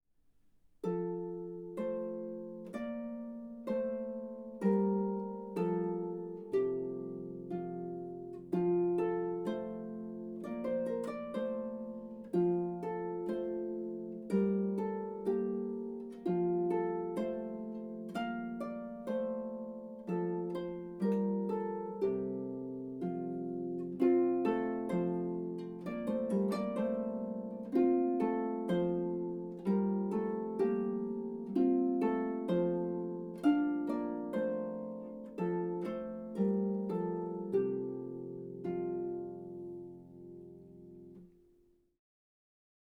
traditional folk song